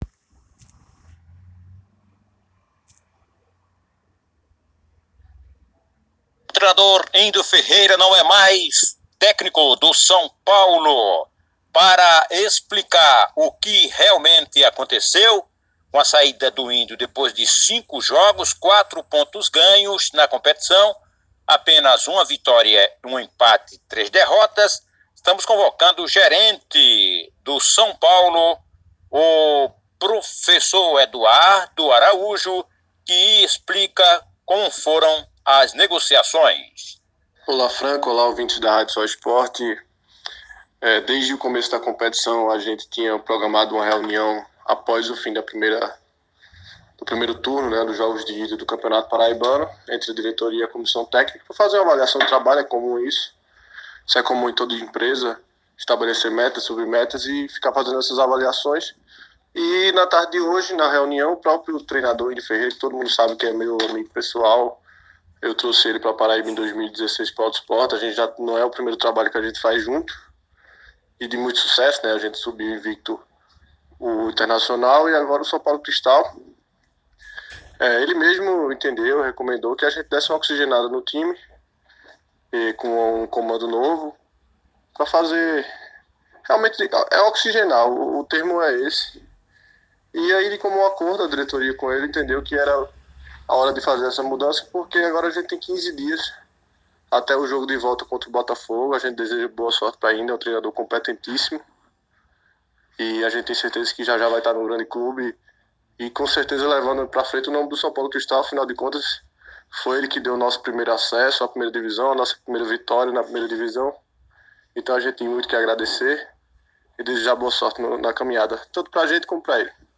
fala na rádio Soesporte Podcast